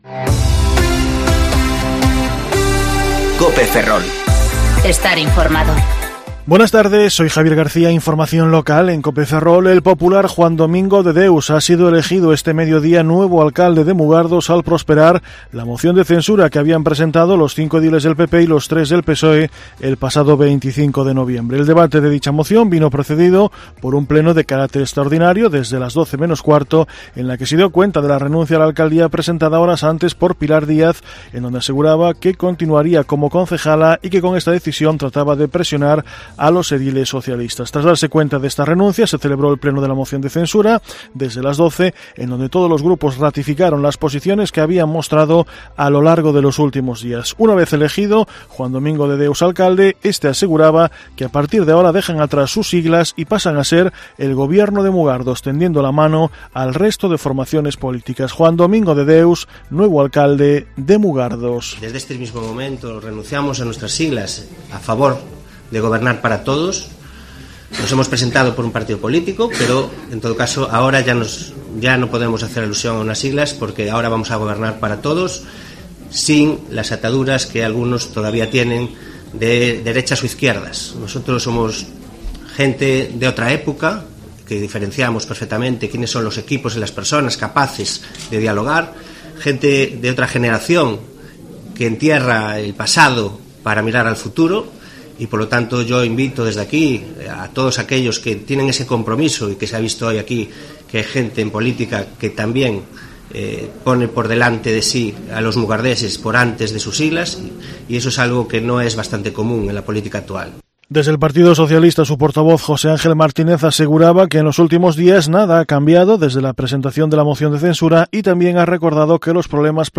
Informativo Mediodía Cope Ferrol 10/12/2019 (De 14.20 a 14.30 horas)